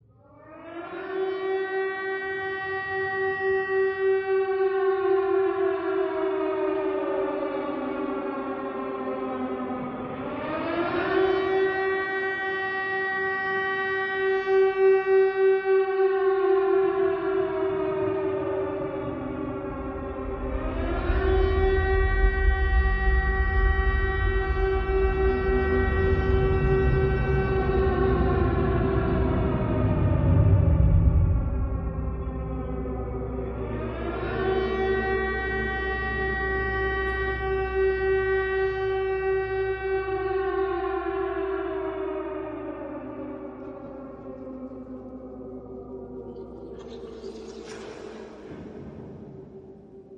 Звуки паники
В коллекции представлены тревожные крики, хаотичные шаги, гул взволнованной толпы и другие эффекты для создания напряженной атмосферы.
Звук сирены в момент глобальной катастрофы